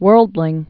(wûrldlĭng)